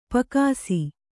♪ pakāsi